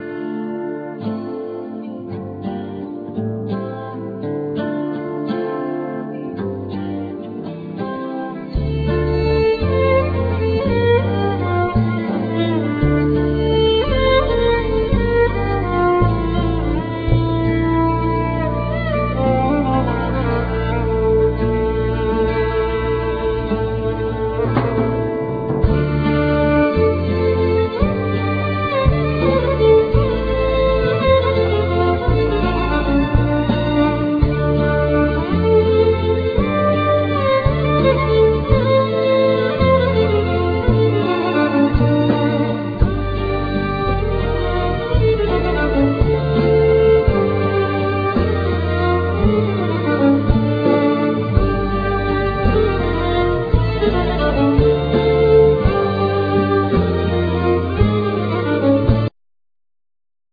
Violin,12 hornes acoustic guitar
Oud
Santouri
Bass
Keyboards
Drums
Percussions
Lyra